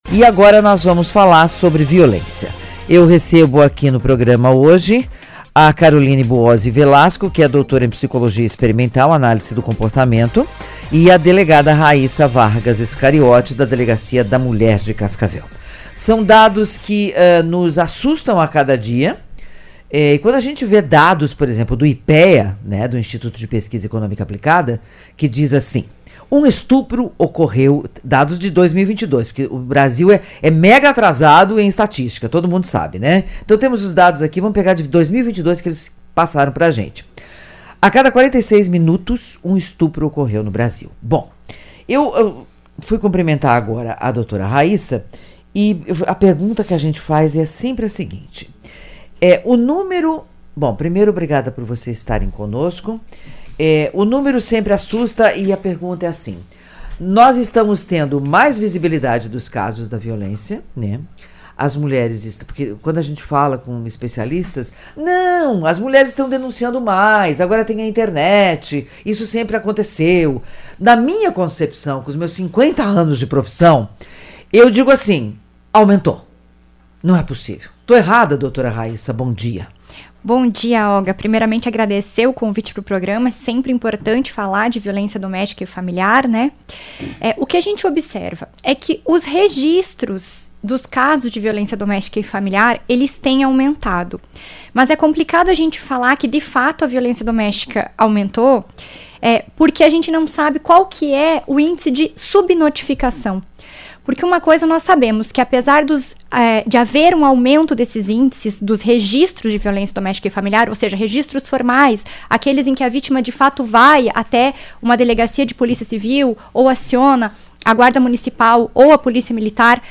psicóloga e doutora em …continue lendo Ouça a reportagem